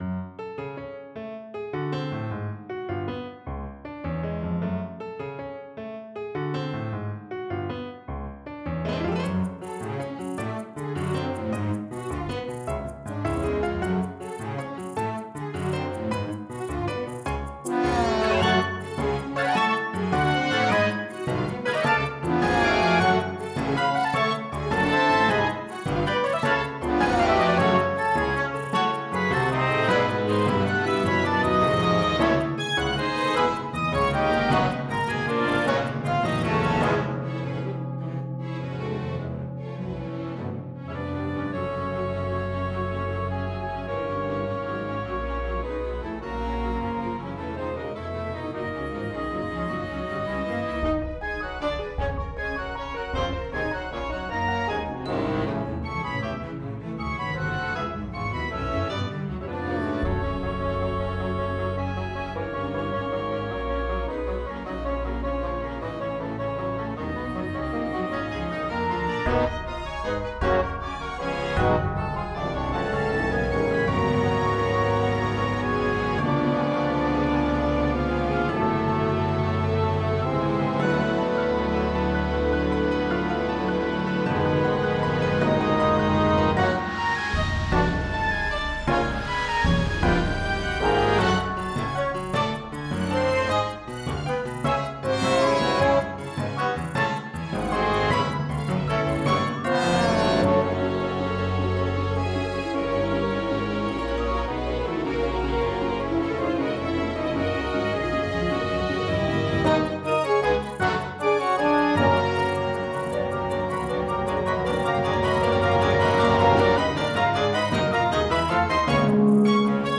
This paper accompanies the authors original symphony for large orchestra, folkloric flux.